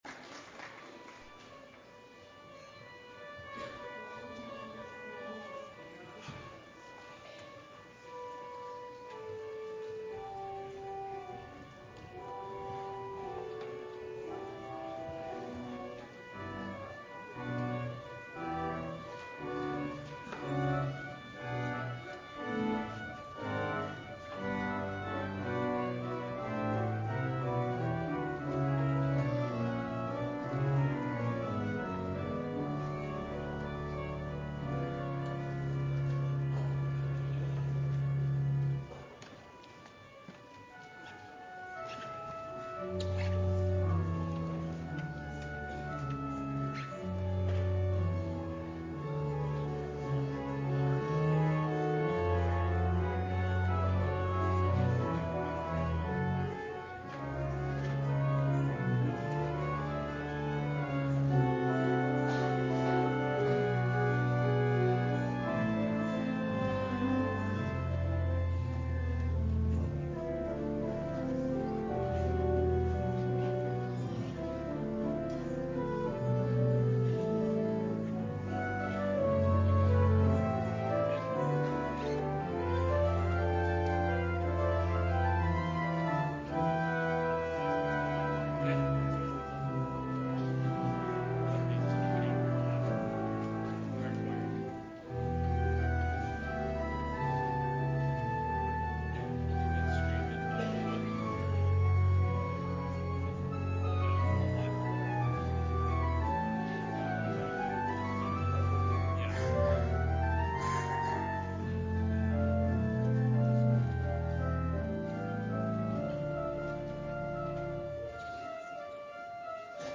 Lessons and Carols